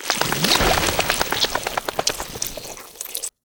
shake.wav